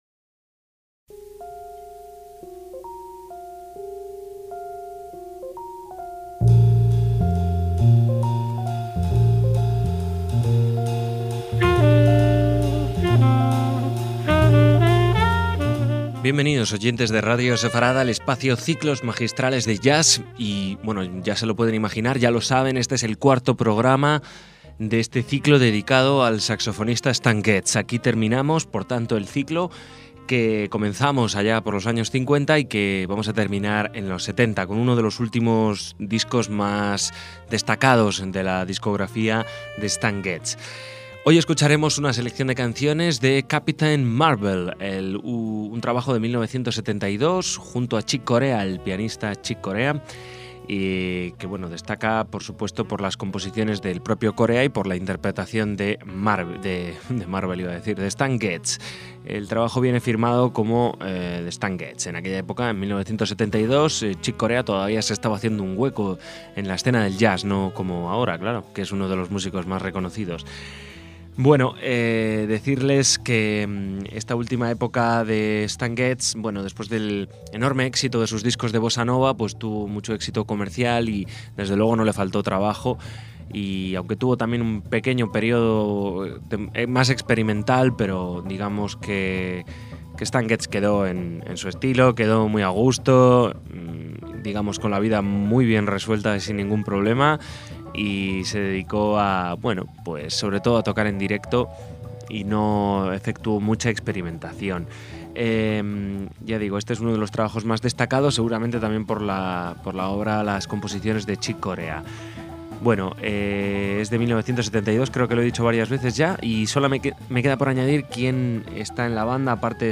saxofonista
en bajo
en percusiones
en la batería
jazz latino y la fusión
sonido dulce y directo